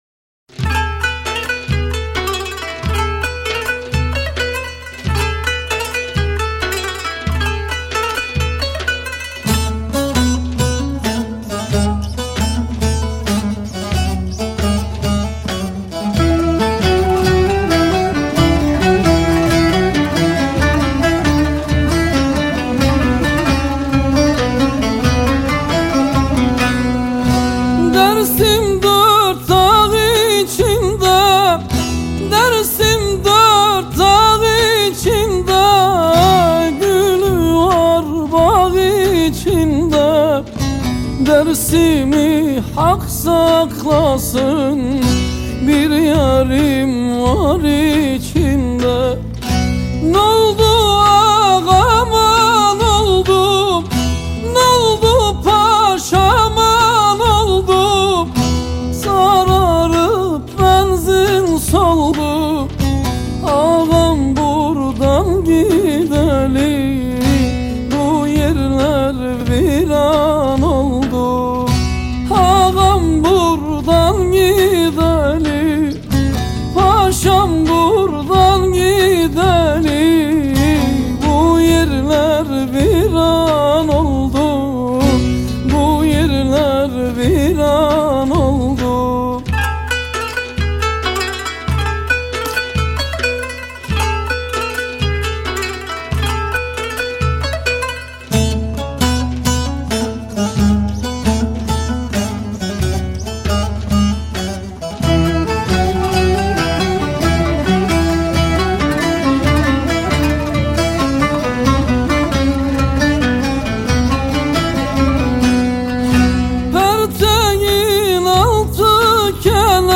موسیقی احساسی و عربسک ترکیه‌ای